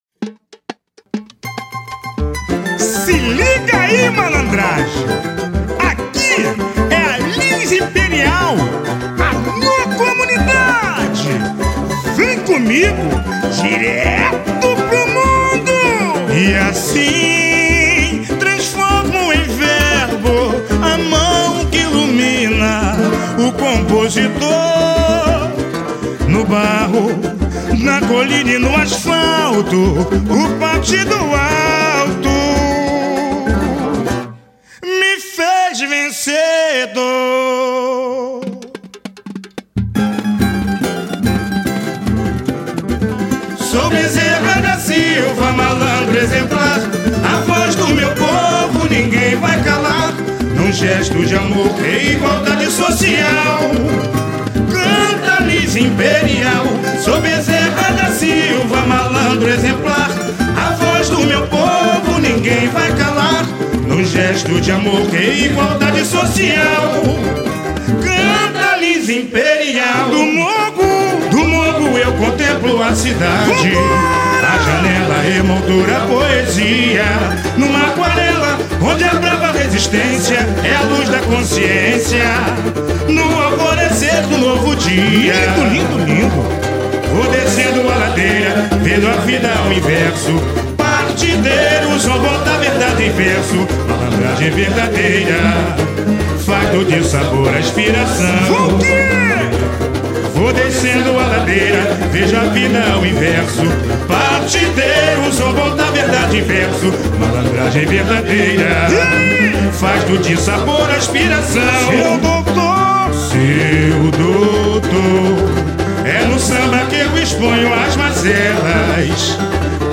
Samba 2